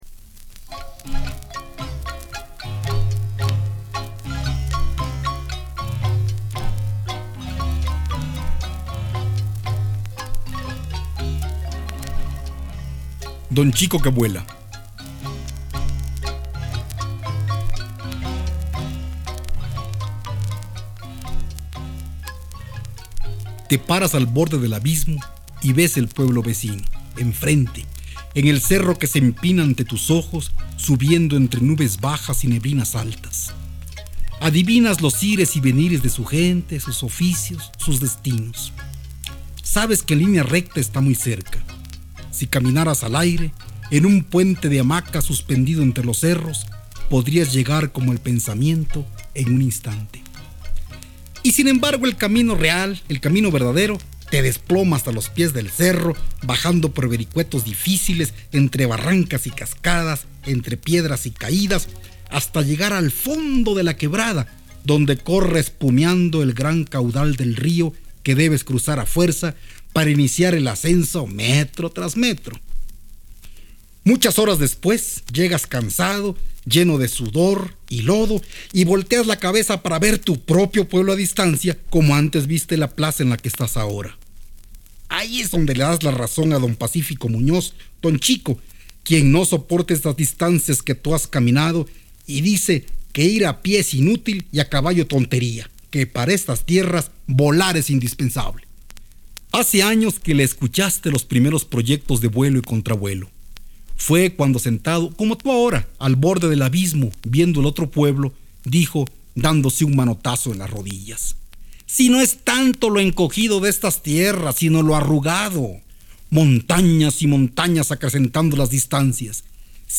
Hablar de la figura de Eraclio Zepeda, de lo que representa para el cuento chiapaneco, es una tarea titánica ya que no hay punto de partida ni punto final, este escritor a lo largo de su vida se dedicó a crear historias de una manera magistral y compartió muchas otras de forma oral, una forma oral única, que atrapaba y aunque se deseará no podía dejar de esucharse y sentirse como un niño divertido ante tanta fantasía.
Autor: Eraclio Zepeda Fecha: 1987 Disco: 33 rpm long play Producción: Voz viva, Universidad Nacional Autónoma de Mexico